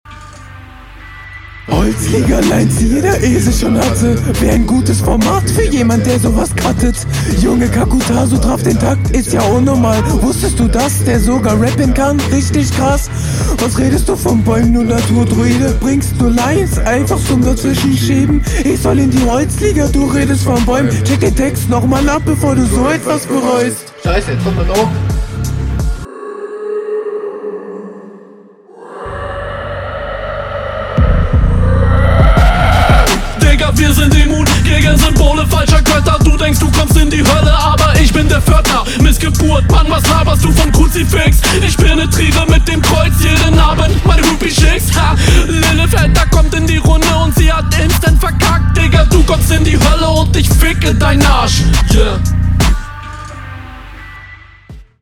Das Soundbild klingt sehr durcheinander und der stimmeneinsatz geht auch besser.